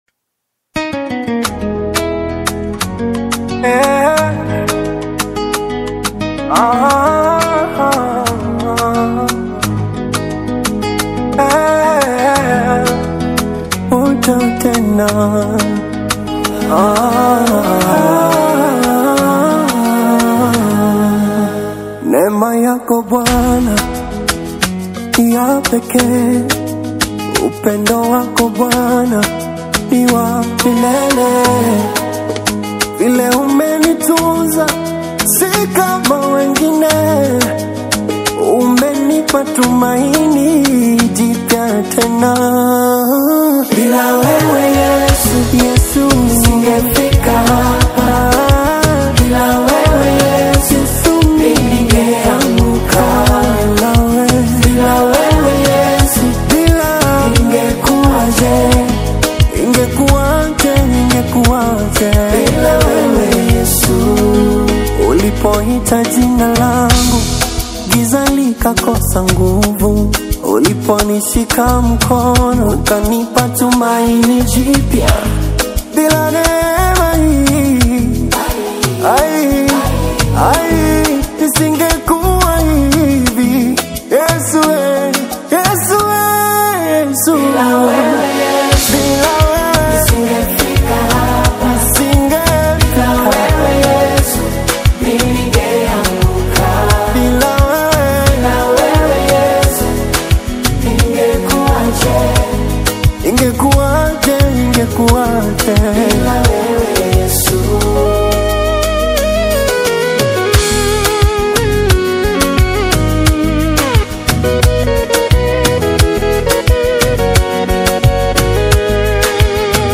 Tanzanian gospel
smooth vocals and high-quality production
a moment of raw, vertical worship